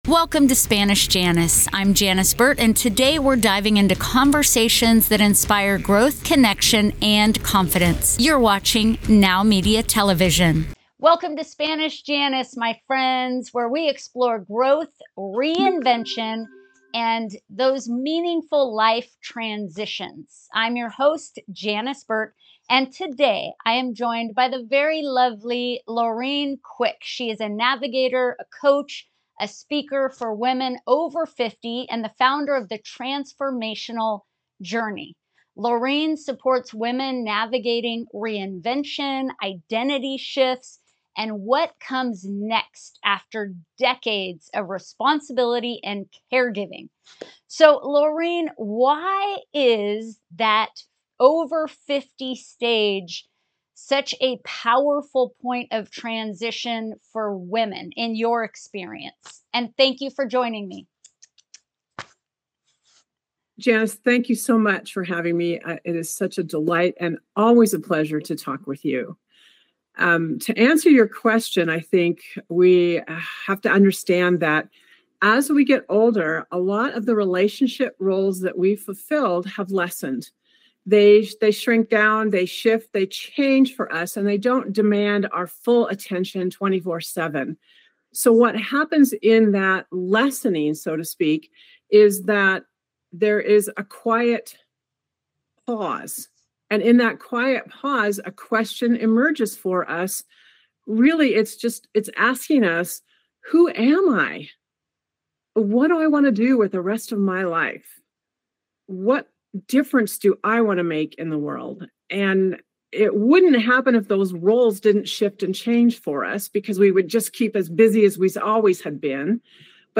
An inspiring conversation about growth, confidence, emotional healing, self-discovery, and new stages of life.